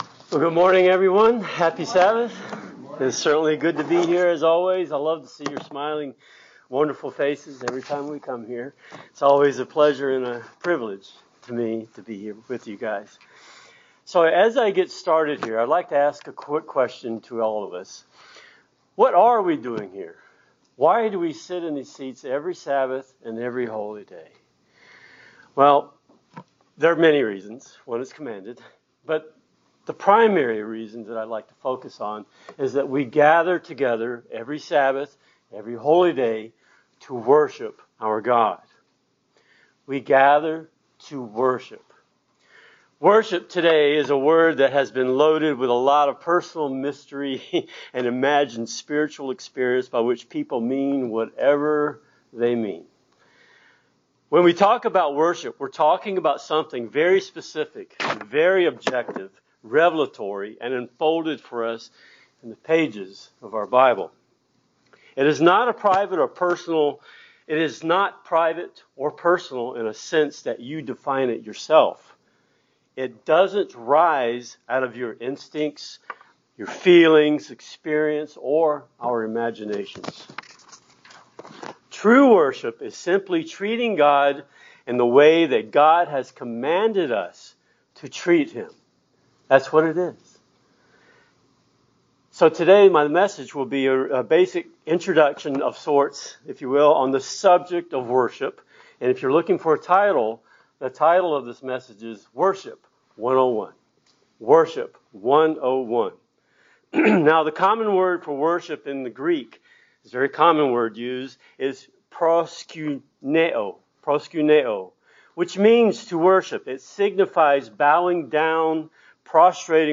Sermon
Given in Buford, GA